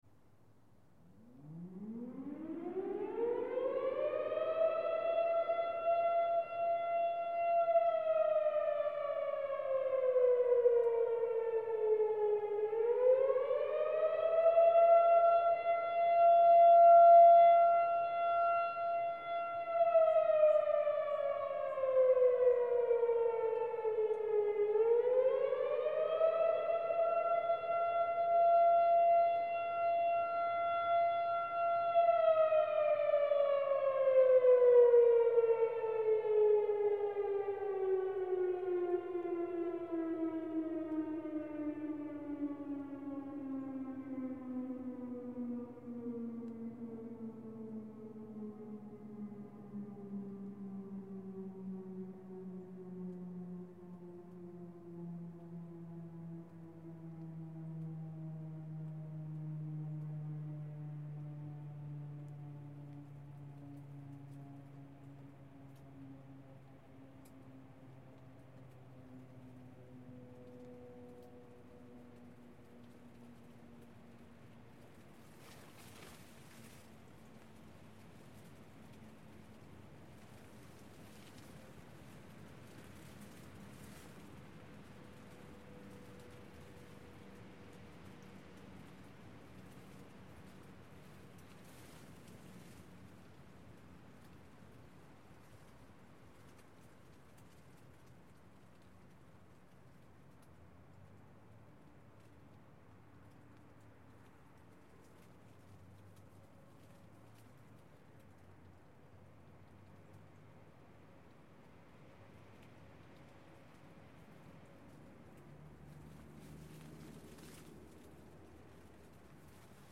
Здесь собраны разные варианты: от далеких раскатов до близкого схода снежной массы.
Звук сирены в горах предупреждает о приближении лавины